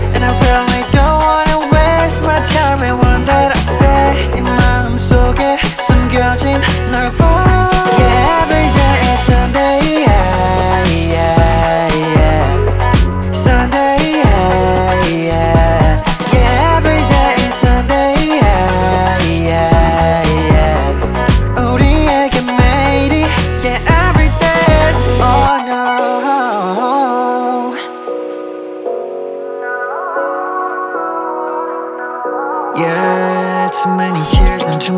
Жанр: Поп / R&b / K-pop / Соул